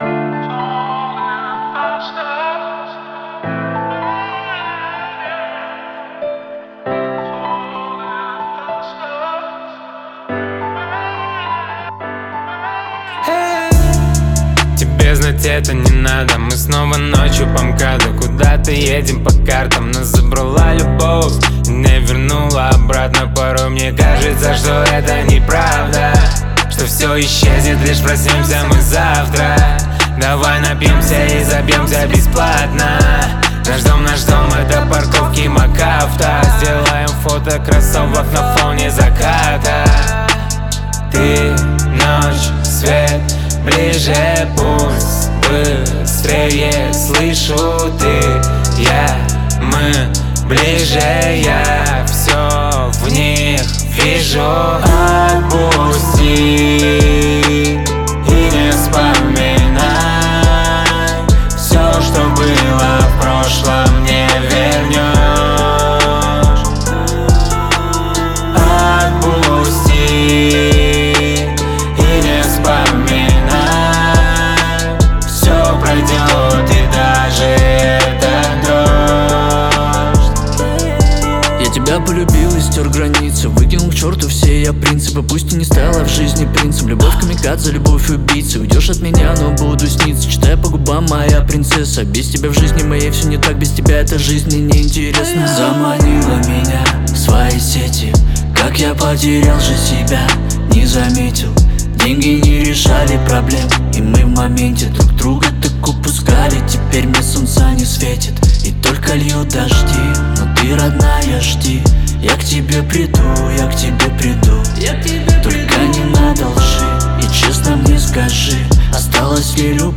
это трек в жанре поп с элементами электронной музыки
атмосферными синтезаторами и мощными вокалами